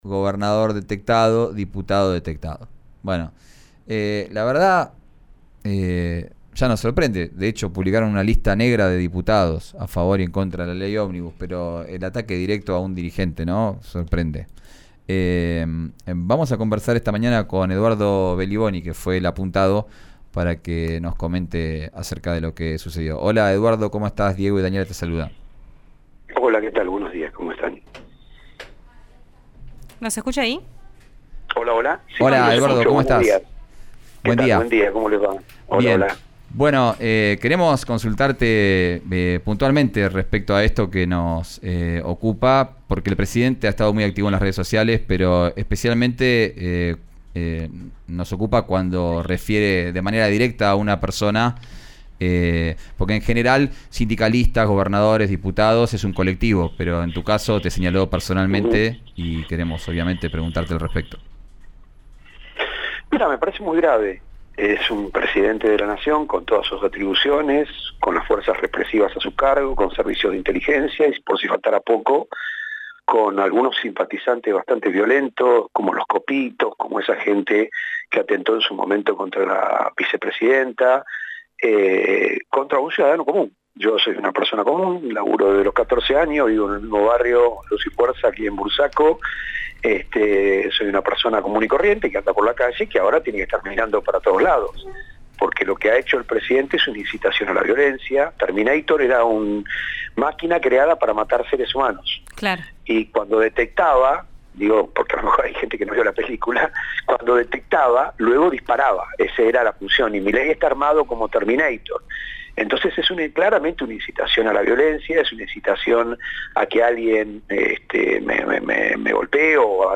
Escuchá a Eduardo Belliboni en «Vos al aire» por RÍO NEGRO RADIO.